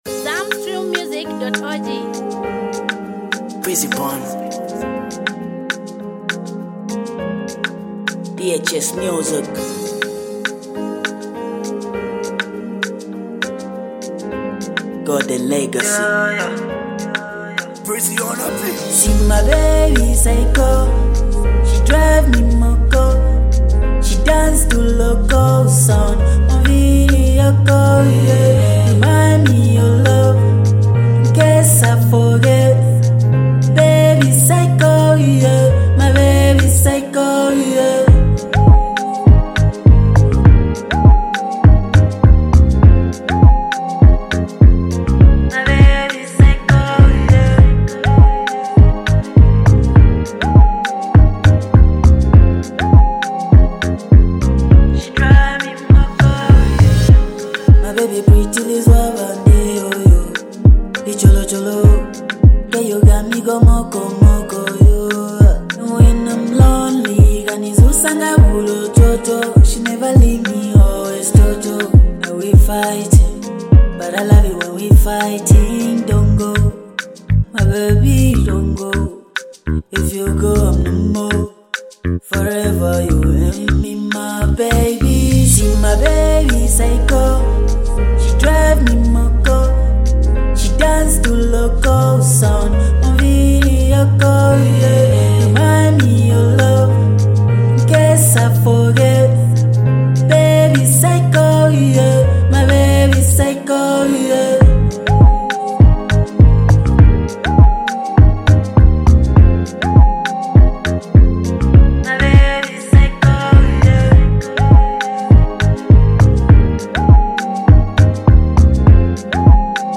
a unique vibrant sound